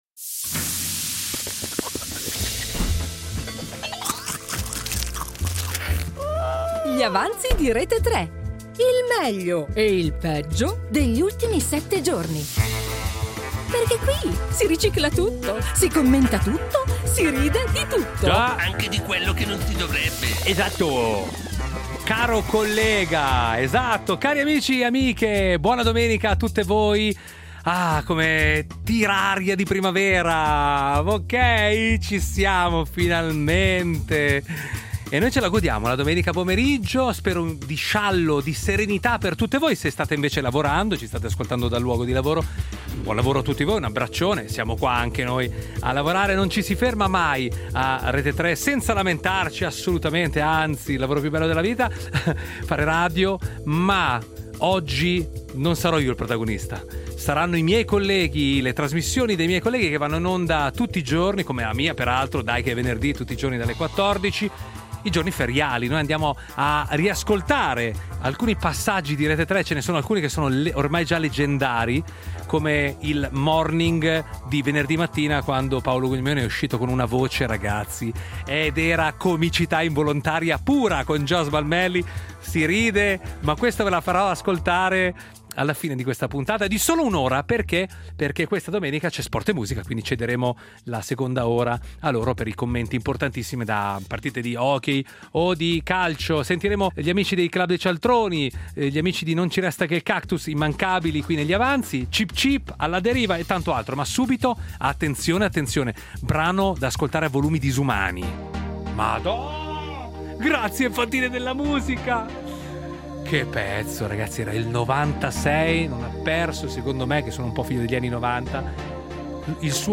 Due ore di musica, momenti memorabili (o dimenticabili), notizie che hanno fatto rumore e altre che hanno solo fatto vibrare il telefono. Un programma che non butta via niente: si ricicla tutto, si commenta tutto, si ride di tutto.